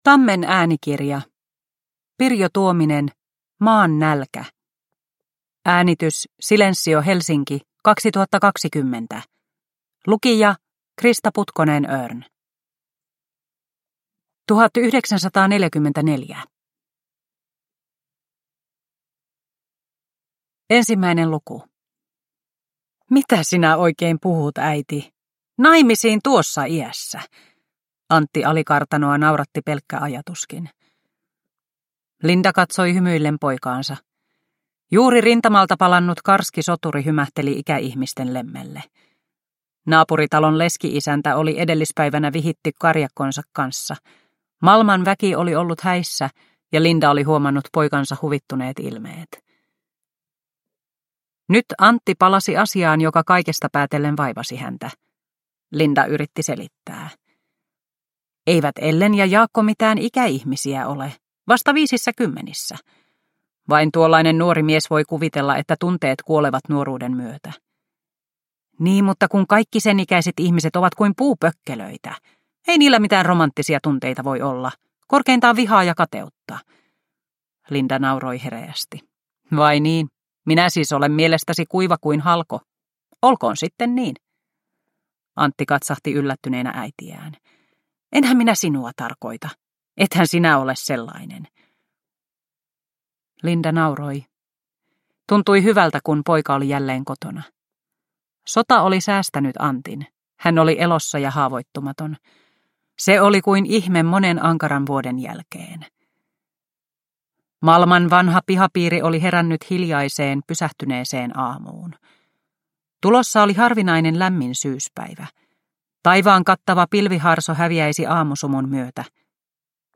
Maan nälkä – Ljudbok – Laddas ner